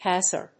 /ˈpɑːsə(r)(米国英語), ˈpæsɜ:(英国英語)/